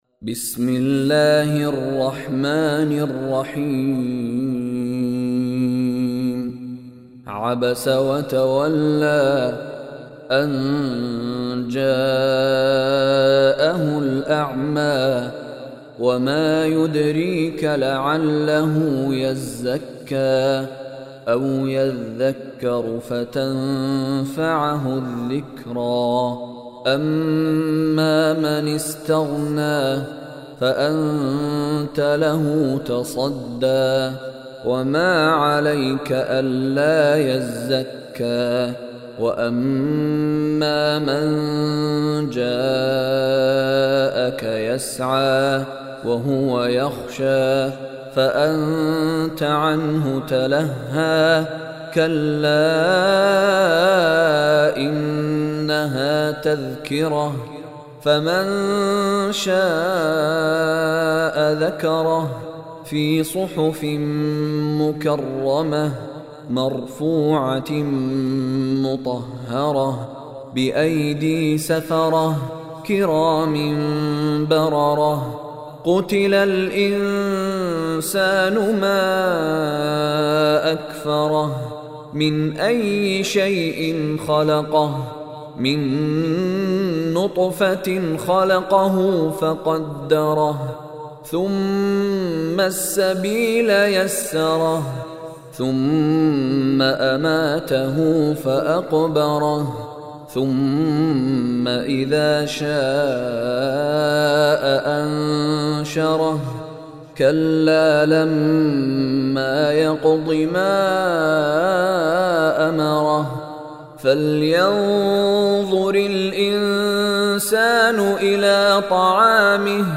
Surah Abasa Beautiful Recitation MP3 Download By Sheikh Mishary Rashid in best audio quality.